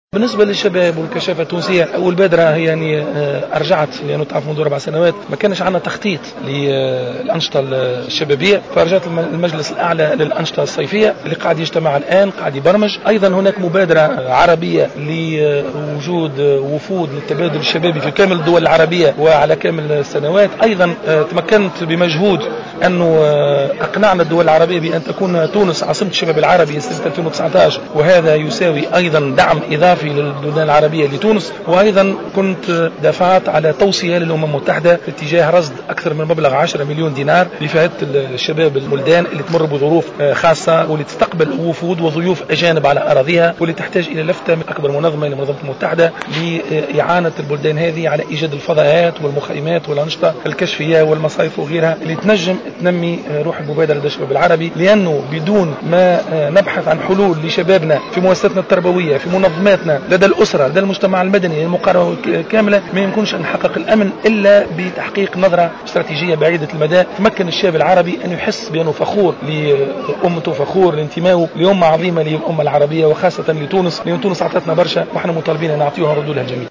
أعلن وزير الشباب والرياضة ماهر بن ضياء على هامش حضوره اليوم الأحد 03 ماي 2015 في جهة المكنين التابعة لولاية المنستير بمناسبة الاحتفال بسبعينية الكشافة أن تونس ستكون عاصمة الشباب العربي لسنة 2019.